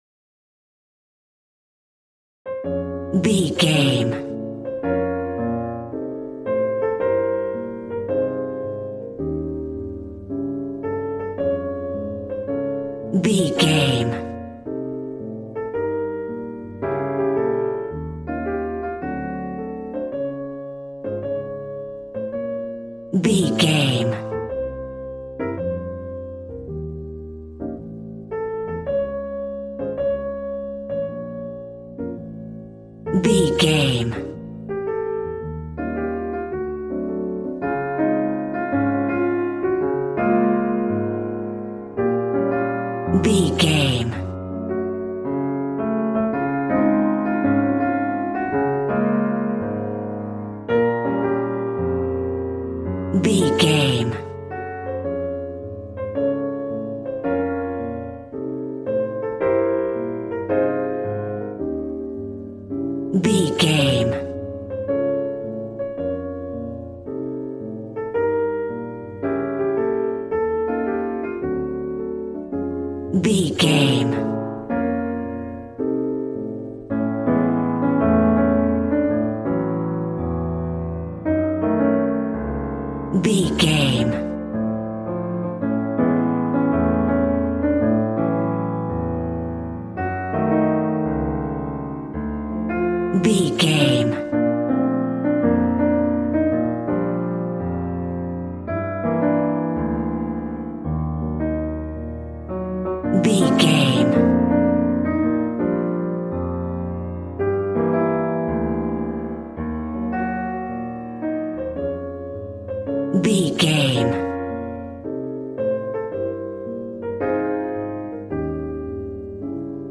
Aeolian/Minor
B♭
smooth
piano
drums